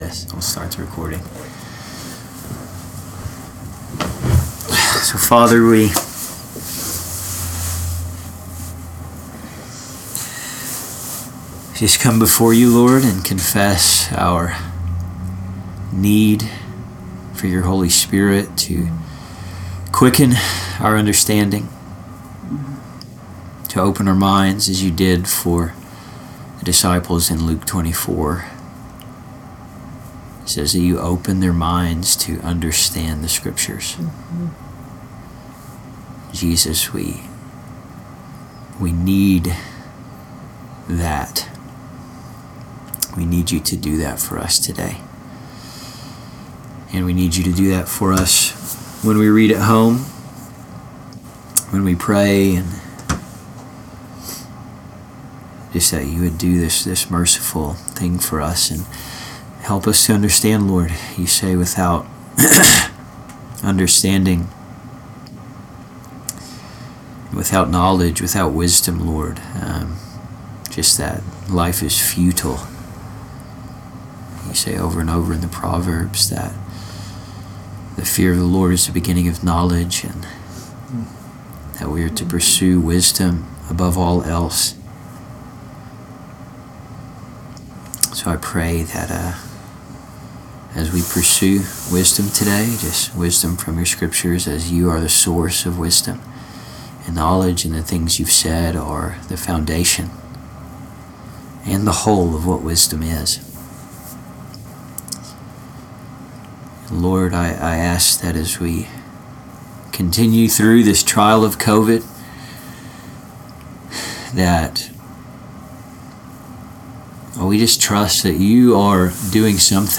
Note* We have upgraded our recording capabilities so that everyone participating in the class can be heard and the volume should be louder as it is undergoing a post recording process.
This was extremely fun to do together and it all happens live for shock value.